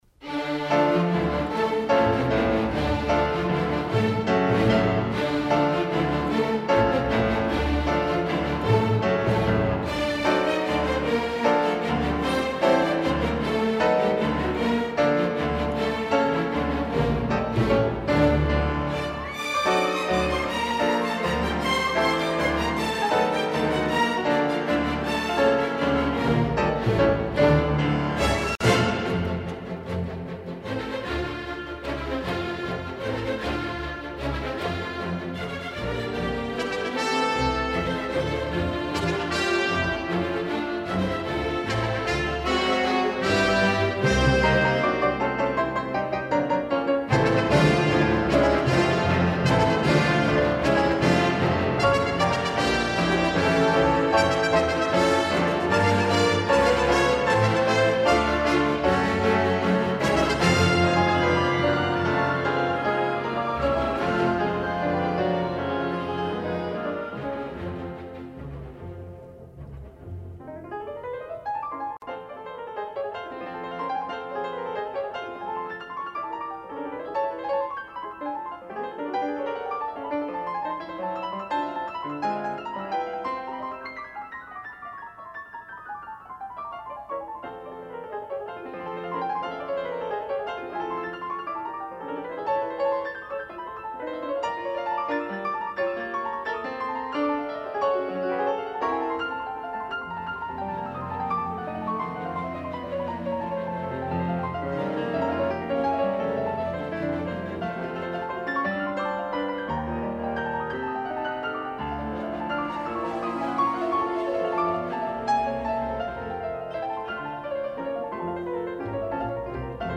快板，乐队奏主题，钢琴奏出和弦
原速度，先乐队后钢琴进行强有力的进行曲格式变奏
钢琴更多活泼谐谑的变奏，并加入弦乐和木管
小快板，双簧管奏优美的旋律，钢琴奏主题片断